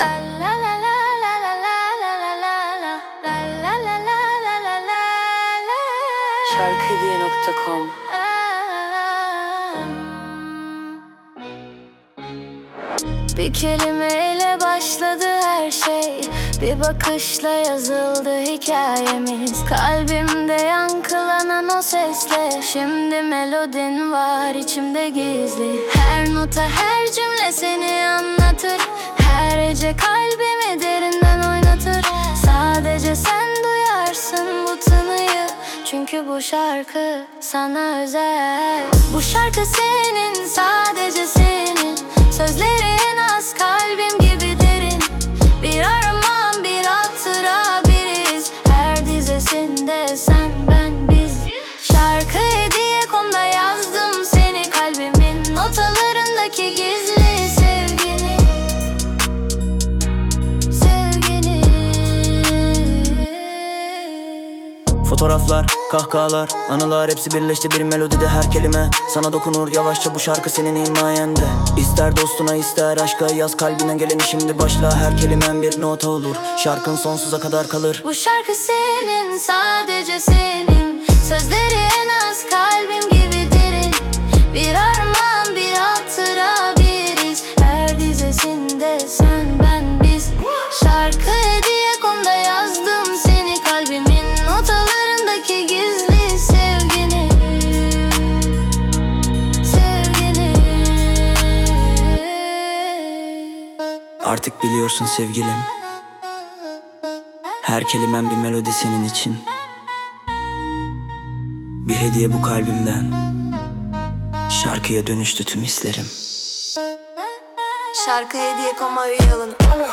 🎤 Vokalli 18.10.2025